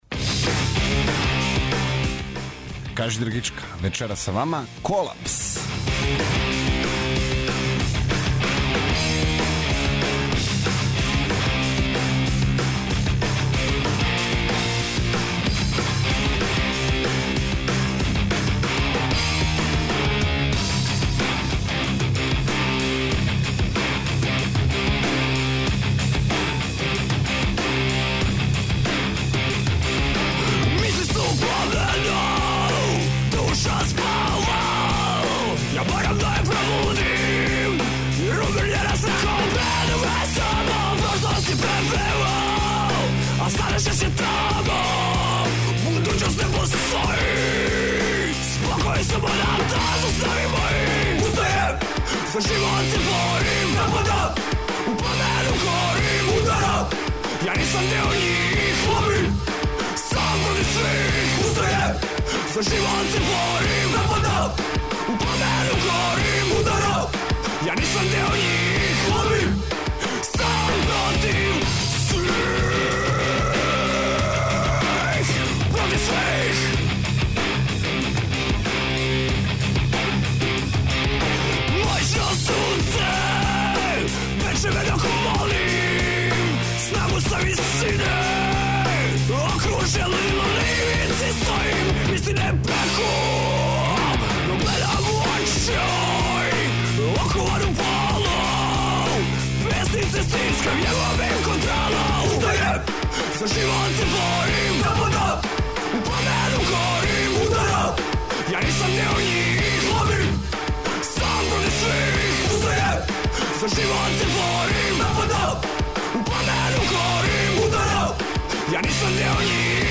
Вечерас се у емисији ''Кажи драгичка'' дружимо са новосадским бендом ''Колапс''! Трећи албум бенда је спреман и тим поводом концертну промоцију одржаће 26. септембра у Новом Саду.